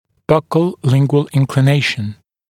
[ˈbʌkl-‘lɪŋgwəl ˌɪnklɪ’neɪʃ(ə)n][ˈбакл-‘лингуэл ˌинкли’нэйш(э)н]наклон в щёчно-язычном направлении, вестибулооральный наклон